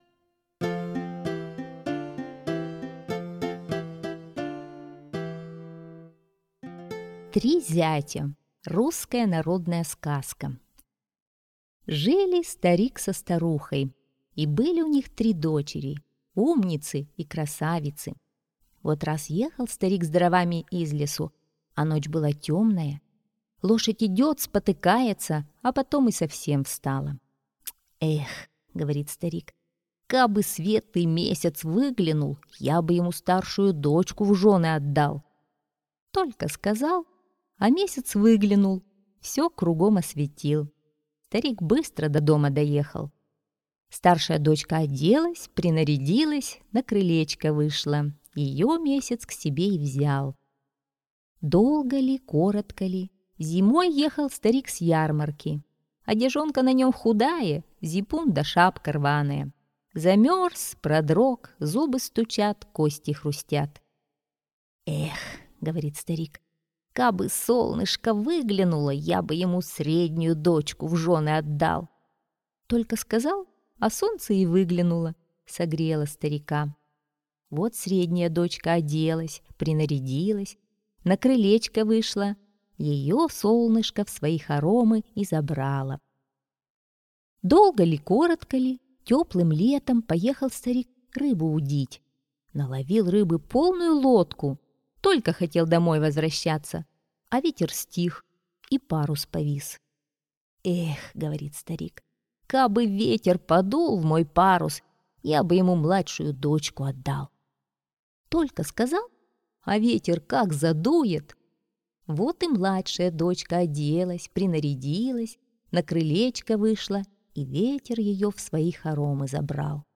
Три зятя - русская народная аудиосказка - слушать онлайн